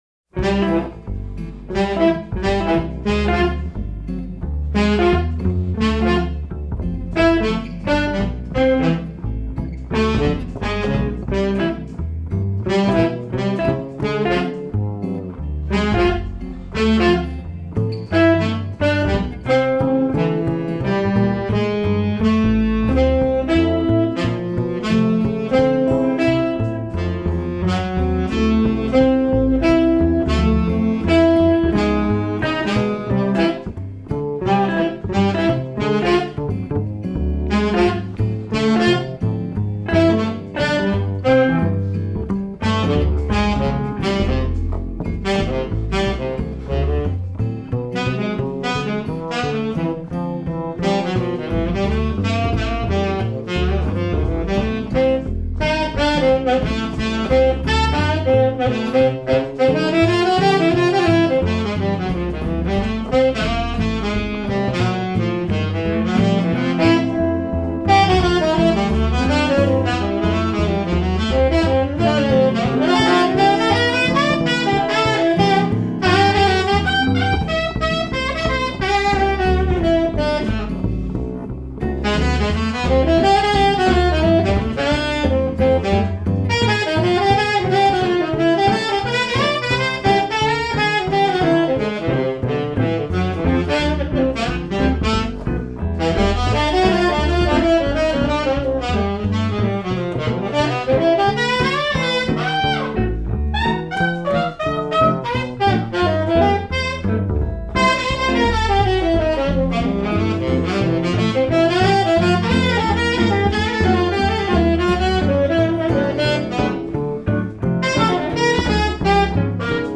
Recorded live at the No Fun Club in Winnipeg
acoustic guitar
electric guitar
sax
bass